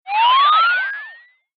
radio_tune.mp3